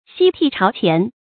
夕惕朝乾 注音： ㄒㄧ ㄊㄧˋ ㄓㄠ ㄑㄧㄢˊ 讀音讀法： 意思解釋： 指終日勤謹慎，不敢懈怠。